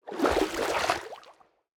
assets / minecraft / sounds / liquid / swim18.ogg
swim18.ogg